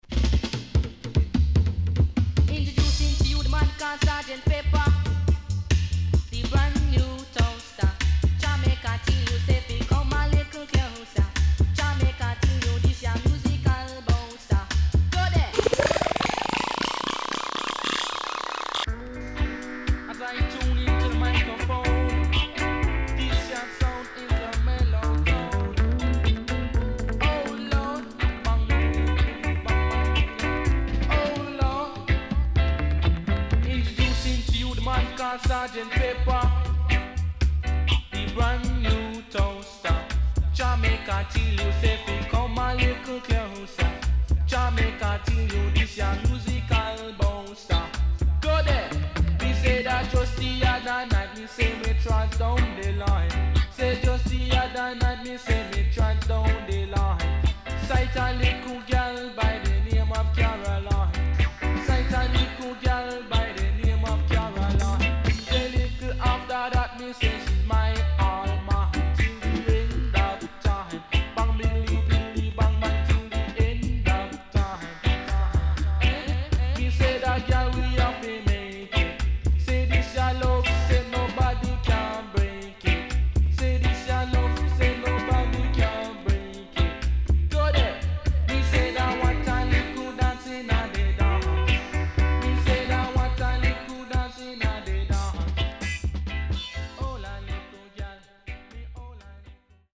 【12inch】